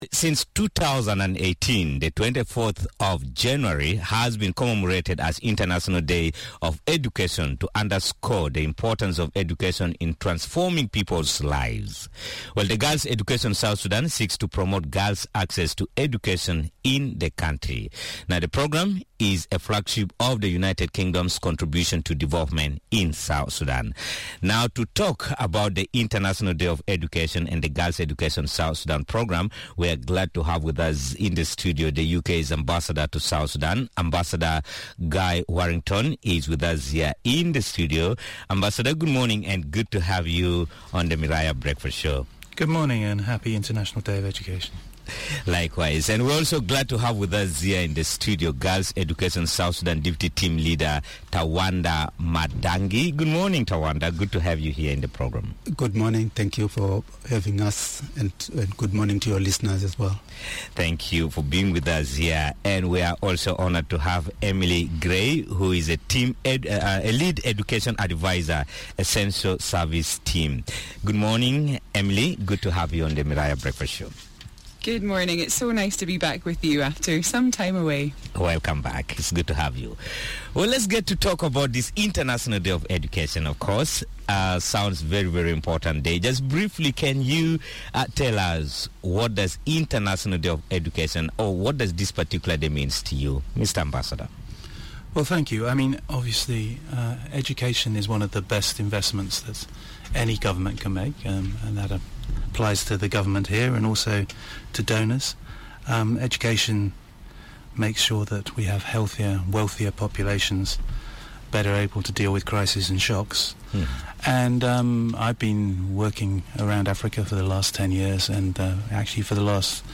Three guests joined the Miraya Breakfast Show to talk about the programme: 1.